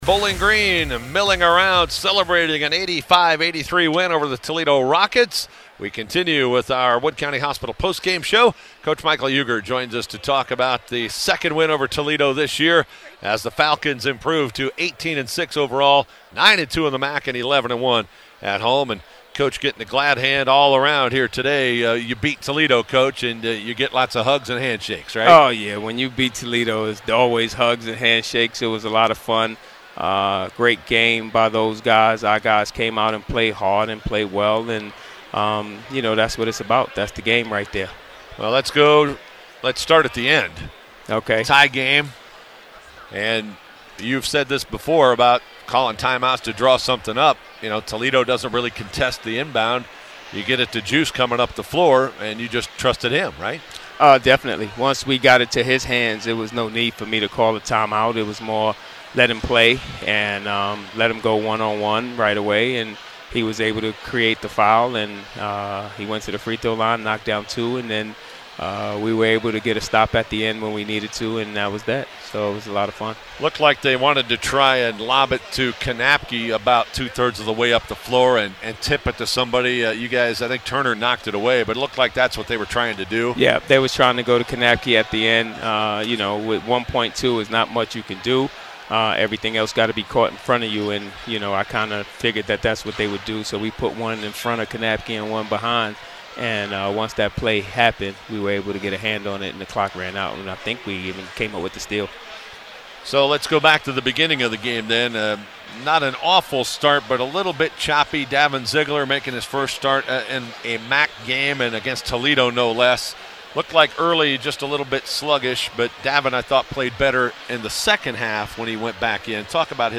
Post-Game Audio: